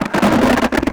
ALIEN_Communication_28_mono.wav